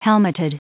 Prononciation du mot helmeted en anglais (fichier audio)
Prononciation du mot : helmeted